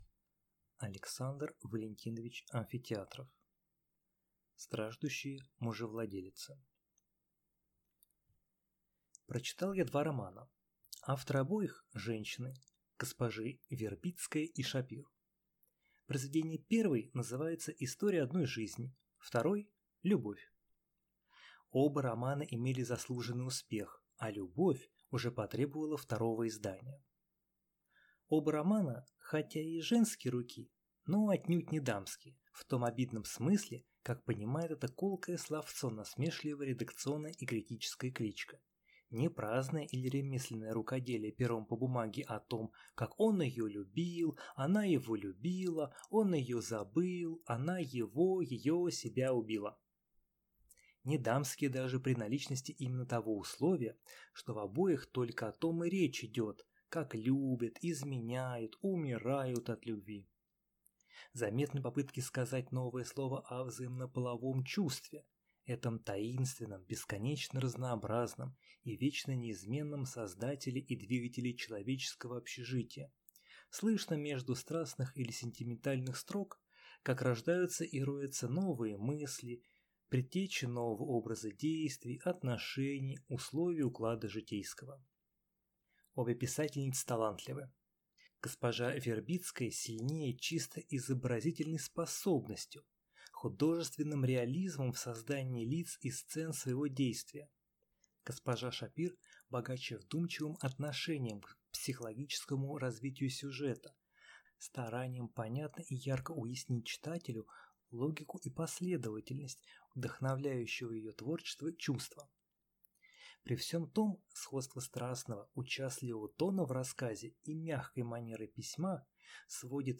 Аудиокнига Страждущие мужевладелицы | Библиотека аудиокниг